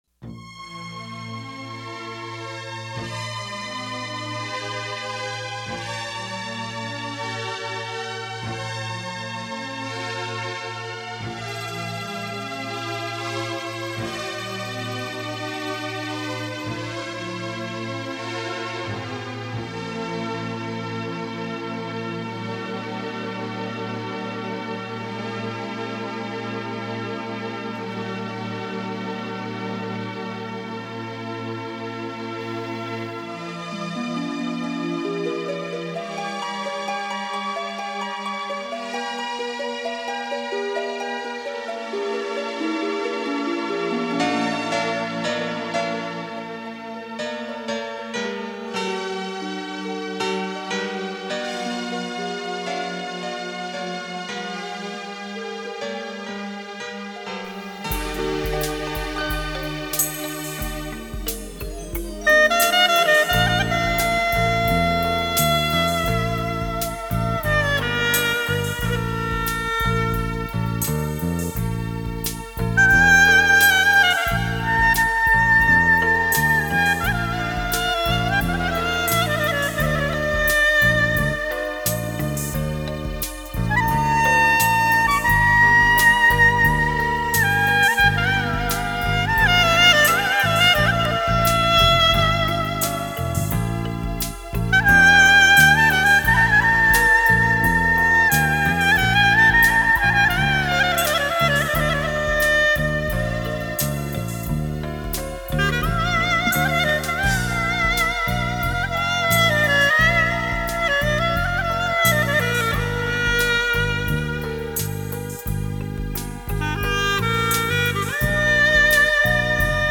հայկական հրաշալի գործիքներից մեկը